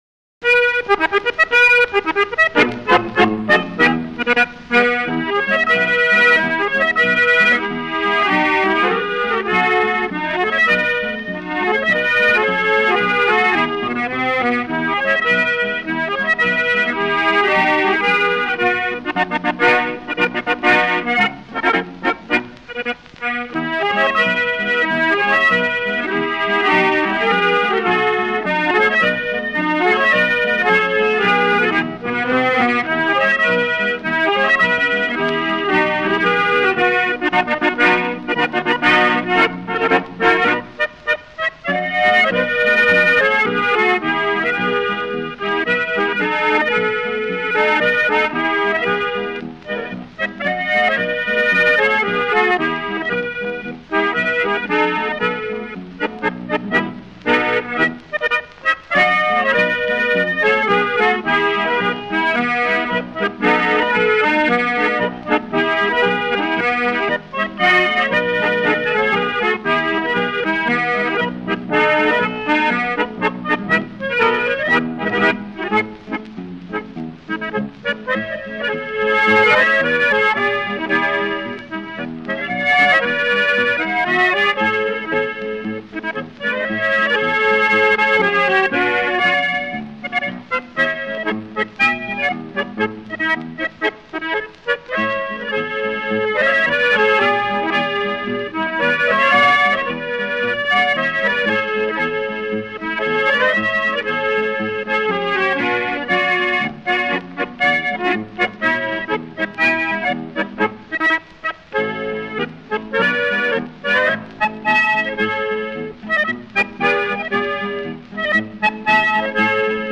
The so-called “Schrammelharmonika” is an accordion intrinsically tied to Viennese folk music, particularly to what is known as Viennese “Schrammel”-music.
Its different ways of playing and ranges of use are shown with the aid of selected musicians and sound samples dating from the beginning of the 20th century up until nowadays.